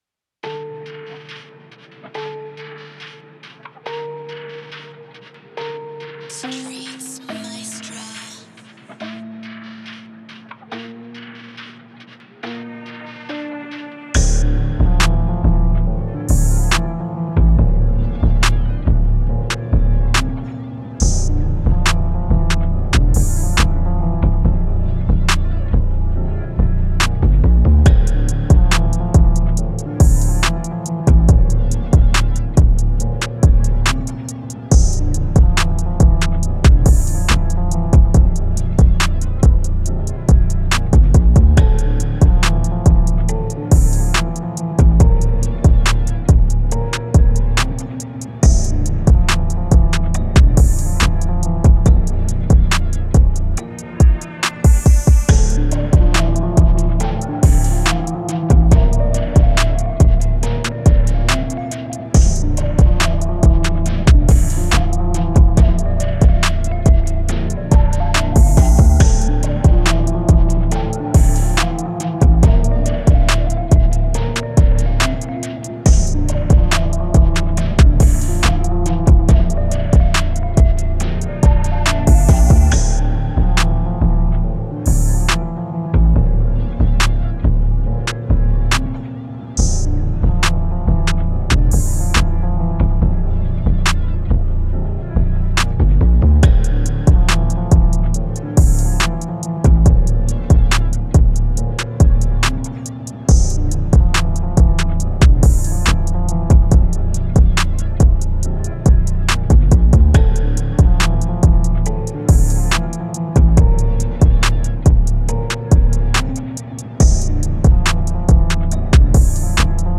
Moods: Dark, intense, dramatic
Genre: Trap
Tempo: 140